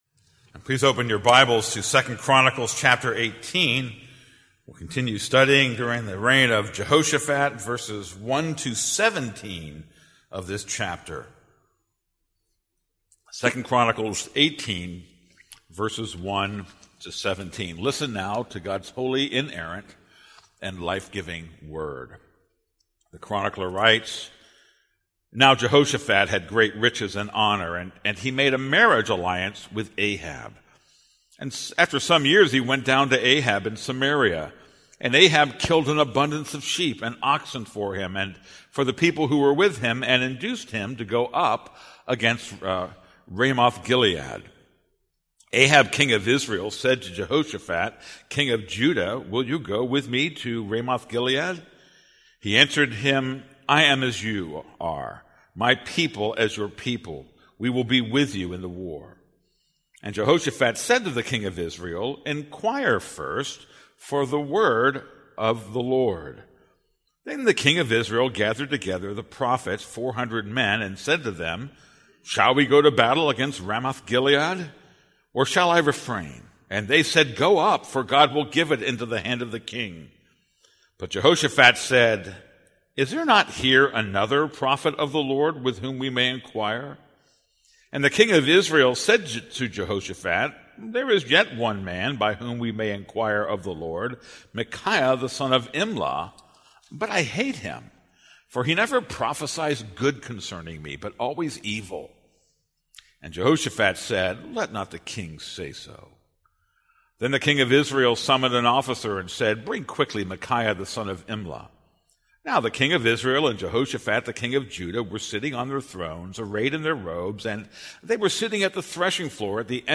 This is a sermon on 2 Chronicles 18:1-27.